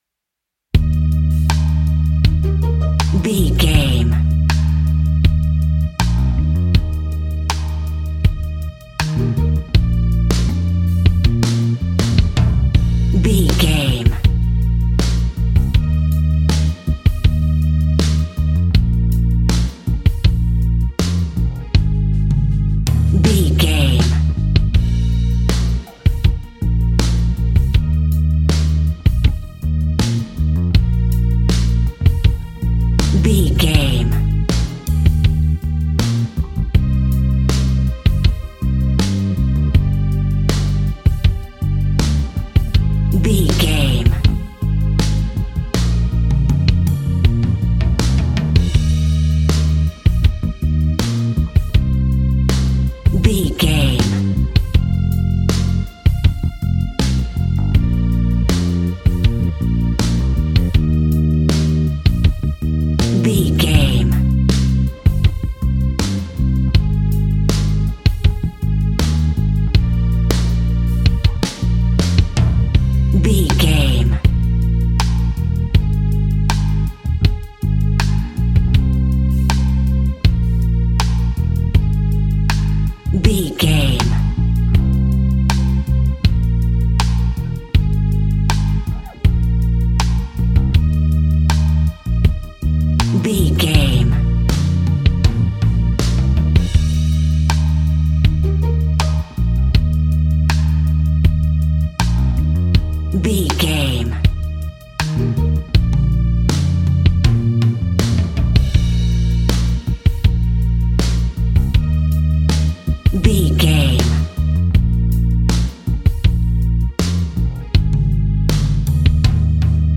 Ionian/Major
E♭
romantic
sweet
happy
acoustic guitar
bass guitar
drums